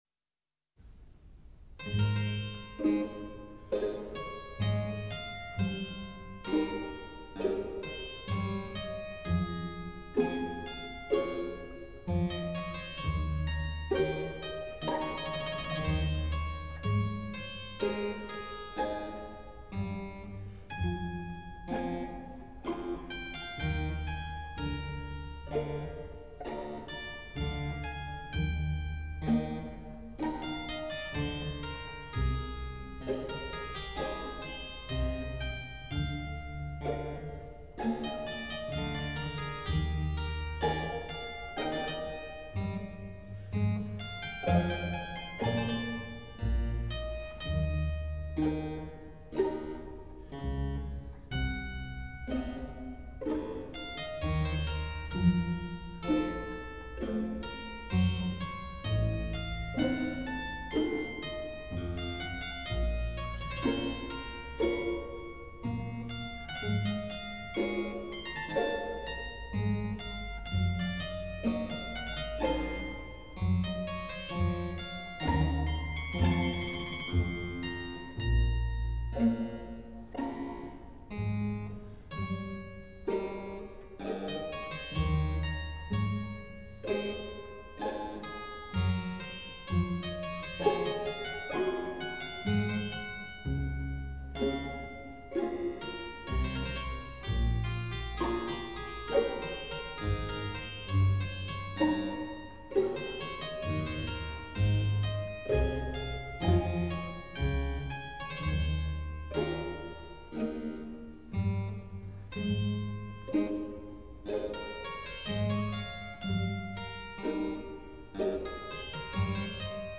Cembalo, Harpsichord, Clavecin
피아노는 해머가 현을 두드려서 소리내는데 비하여 쳄발로는 해머대신 픽(플렉트럼)이라고 하는 것이 현을 퉁겨서 소리를 낸다.
bach_cembalo_conceto5.mp3